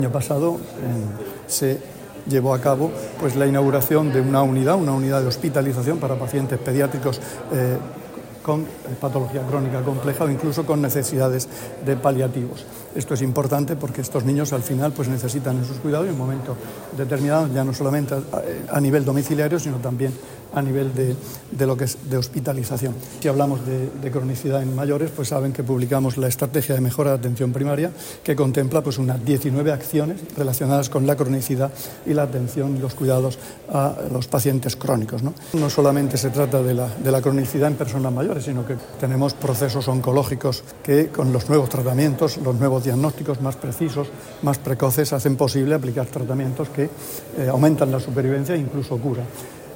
Sonido/ Declaraciones del consejero de Salud, Juan José Pedreño, sobre la jornada 'Atención y cuidado de pacientes crónicos en la Región de Murcia' [mp3].